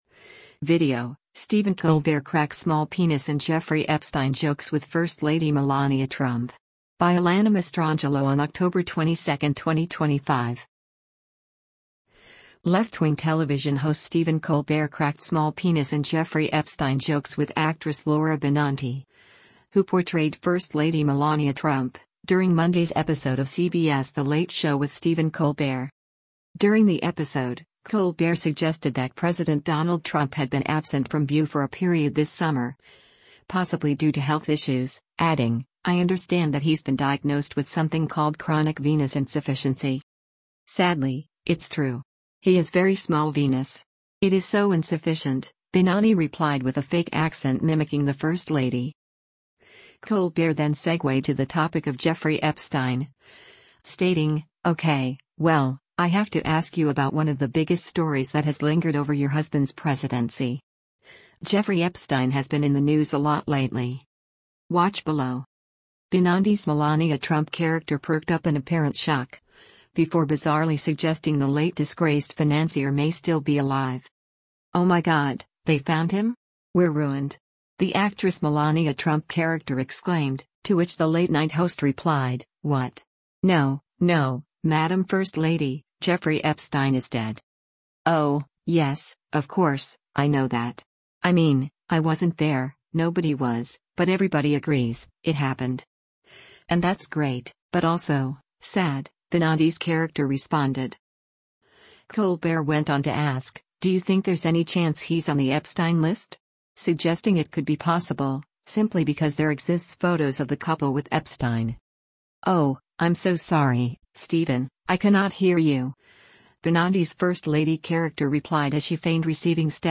Left-wing television host Stephen Colbert cracked small penis and Jeffrey Epstein jokes with actress Laura Benanti, who portrayed First Lady Melania Trump, during Monday’s episode of CBS’ The Late Show With Stephen Colbert.
“Sadly, it’s true. He has very small veenous. It is so insufficient,” Benanti replied with a fake accent mimicking the First Lady.
“Ohhh, I’m so sorry, Stephen, I cannot hear you,” Benanti’s First Lady character replied as she feigned receiving static that rendered her unable to hear the TV host.